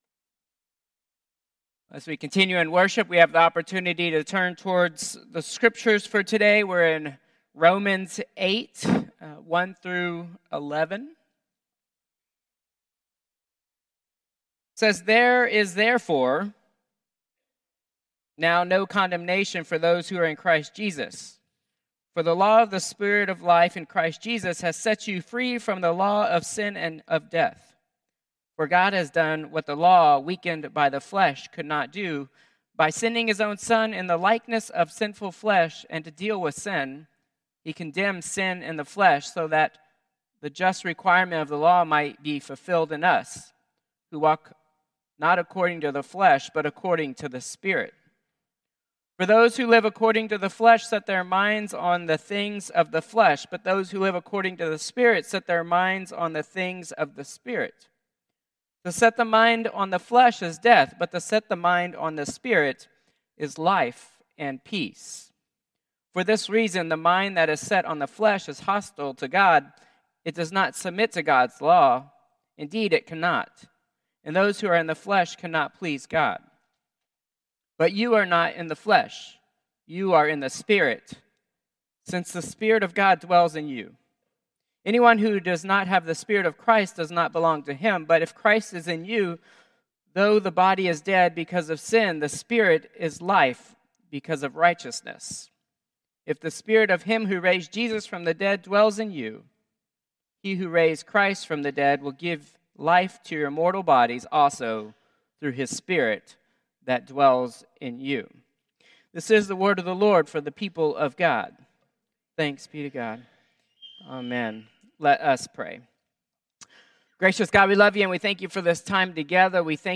Contemporary Service 6/15/2025